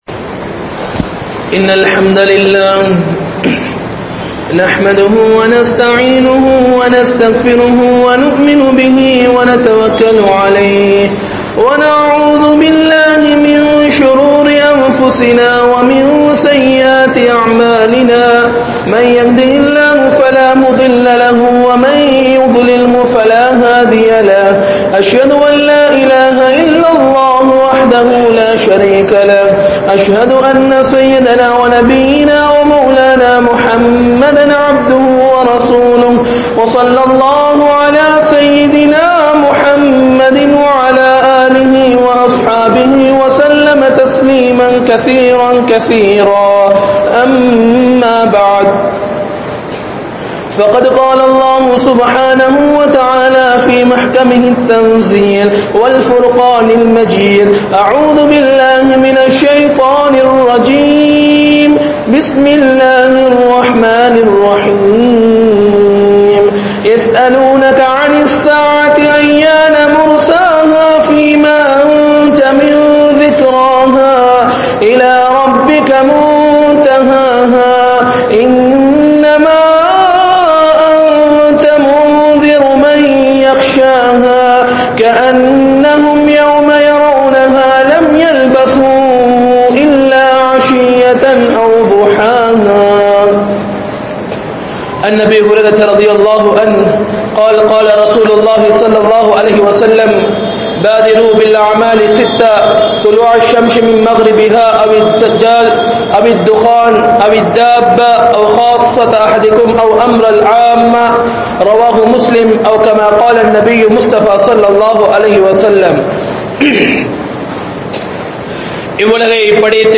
Ulaha Mudivin Adaiyaalangl (உலக முடிவின் அடையாளங்கள்) | Audio Bayans | All Ceylon Muslim Youth Community | Addalaichenai